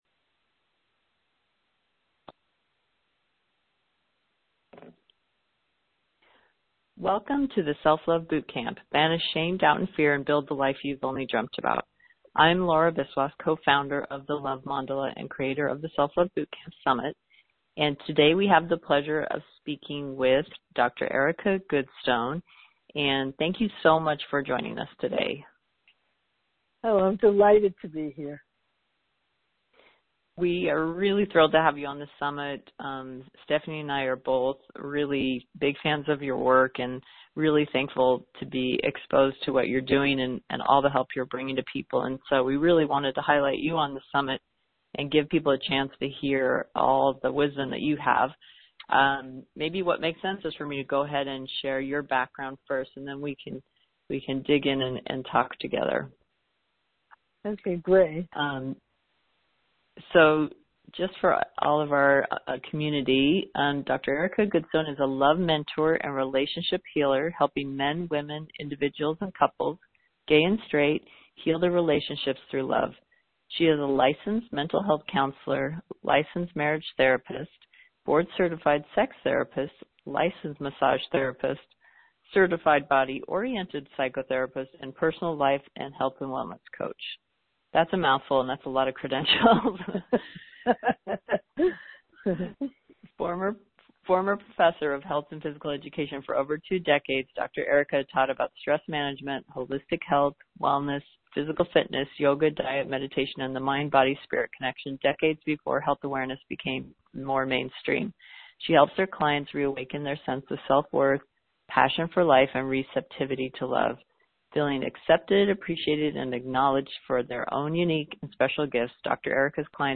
SELF LOVE BOOTCAMP AUDIO INTERVIEW